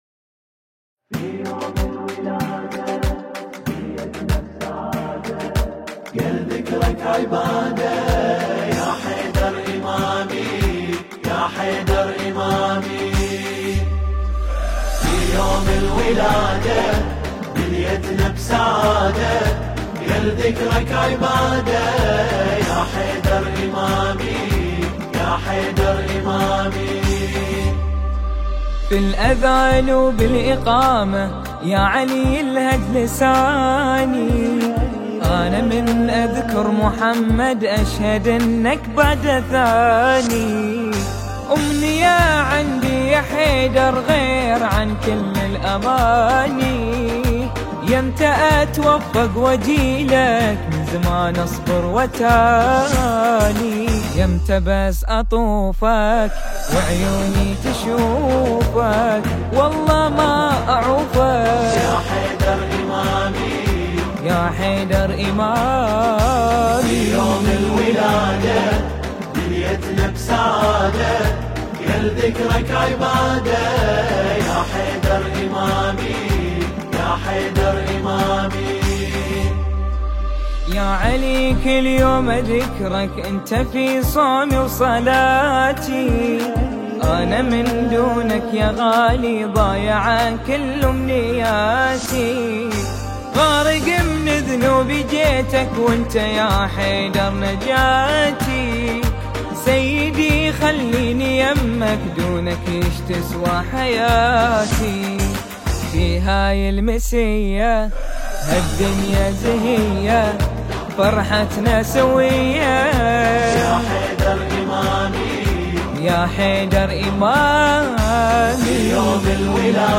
أداء :